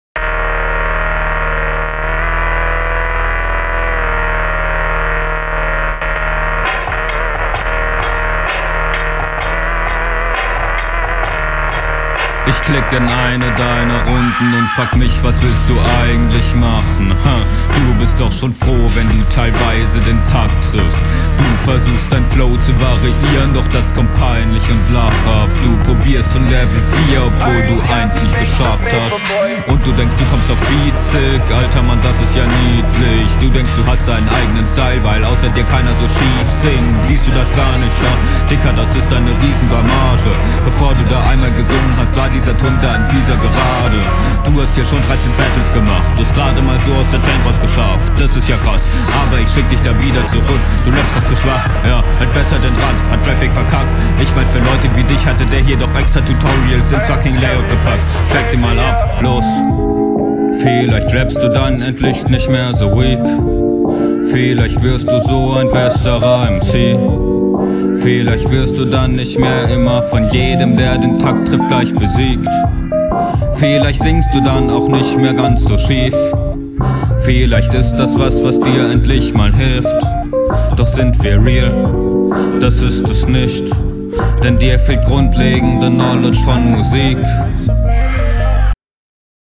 interessanter Stimmeinsatz, find die Idee so zu rappen cool.
Flow: Hier hast du wieder den gelangweilten Flow, der holt mich einfach absolut nicht ab.